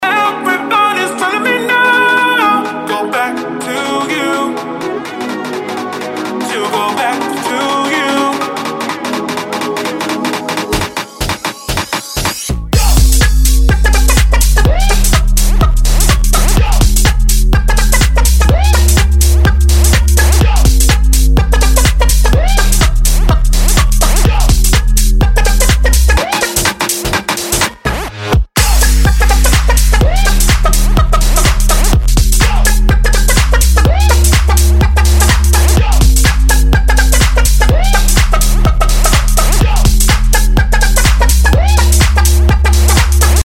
• Качество: 224, Stereo
мужской голос
громкие
dance
Electronic
future house
club